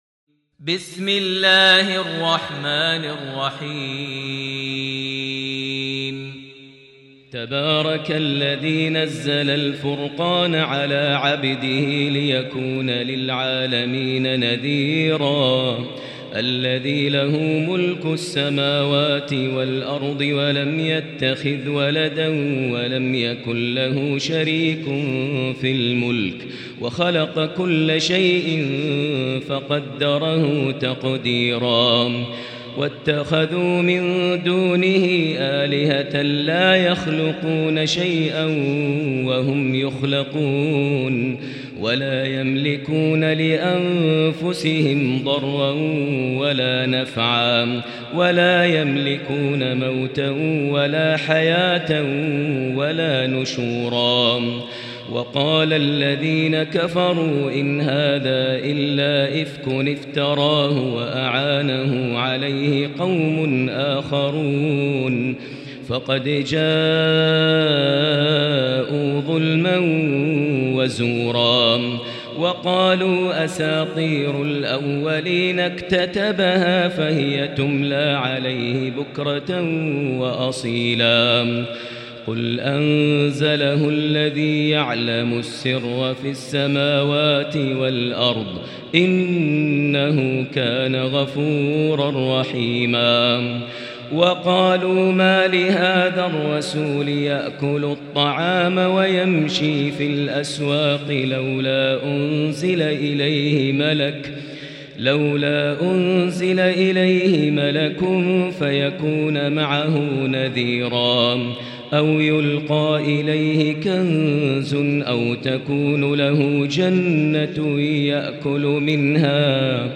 المكان: المسجد الحرام الشيخ: فضيلة الشيخ ماهر المعيقلي فضيلة الشيخ ماهر المعيقلي الفرقان